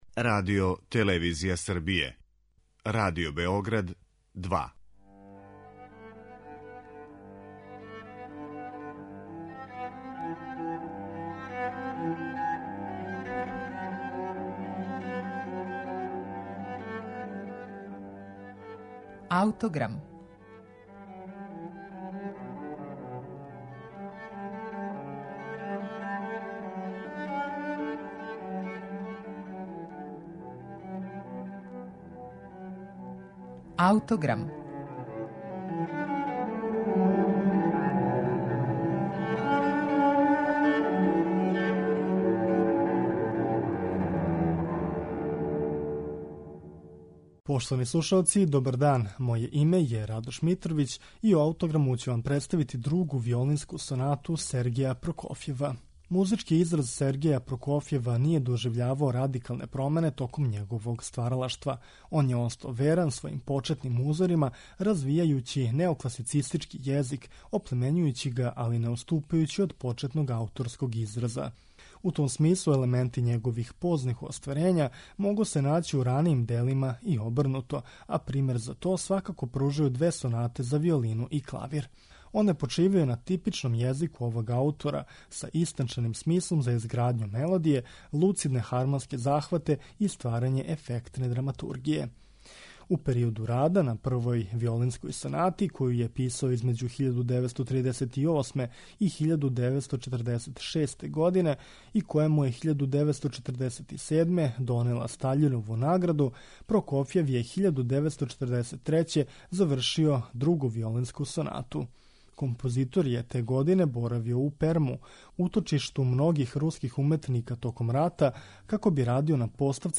Друга сонату за виолину и клавир у Де-дуру